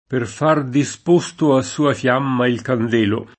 kand%lo] s. m.: Per far disposto a sua fiamma il candelo [